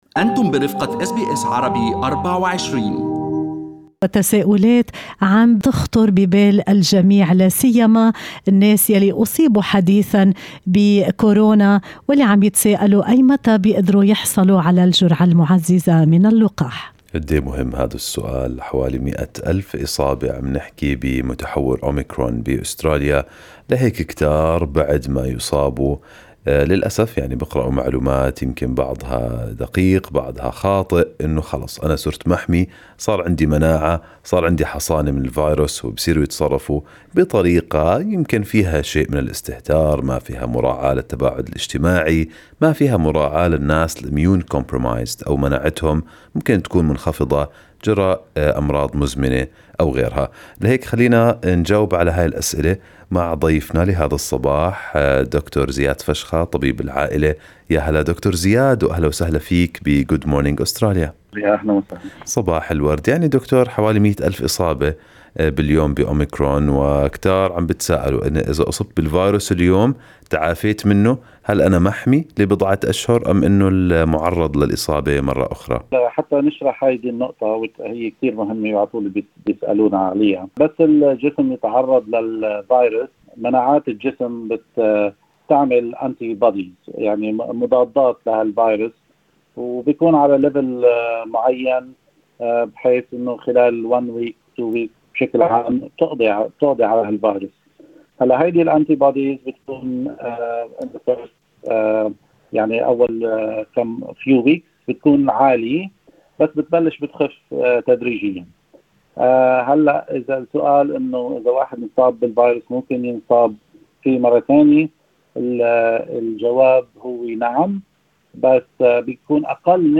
في لقاء له مع أس بي أس عربي24